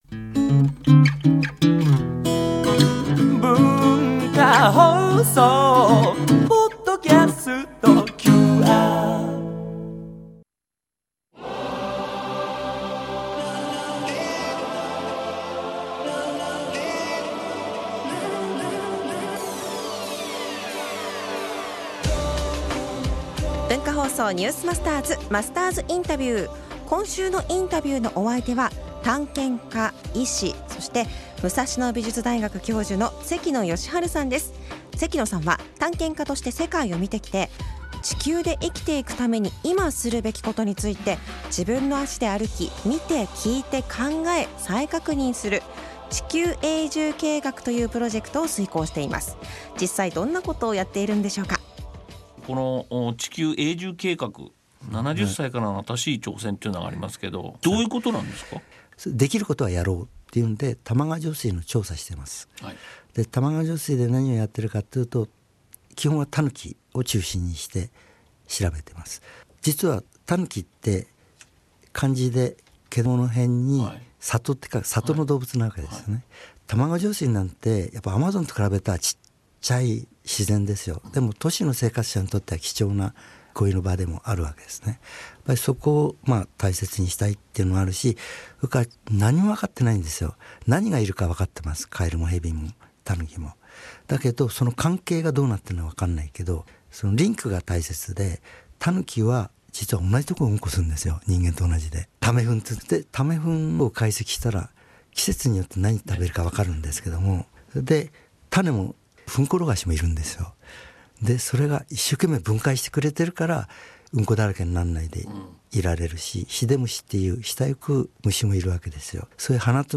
今週のインタビューのお相手は探検家・医師・武蔵野美術大学教授の関野吉晴さん。
（月）～（金）AM7：00～9：00　文化放送にて生放送！